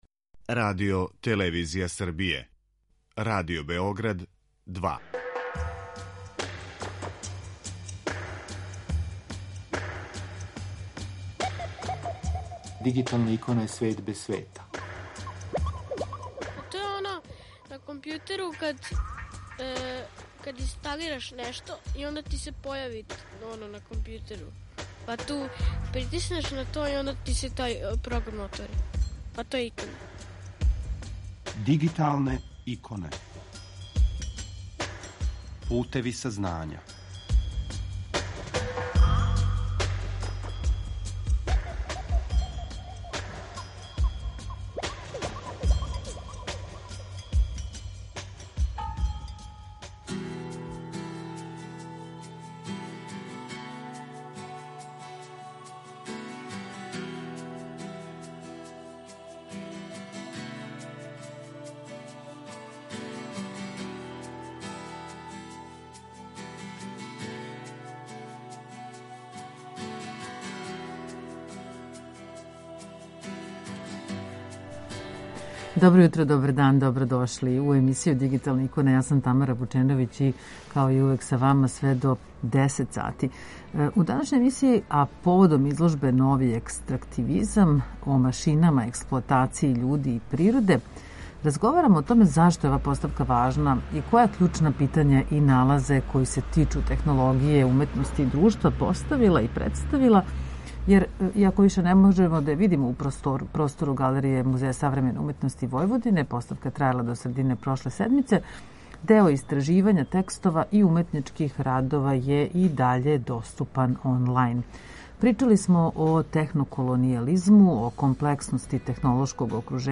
Овога уторка водимо вас у шетњу кроз реалан простор у Новом Саду